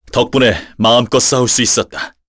Chase-Vox_Victory_kr.wav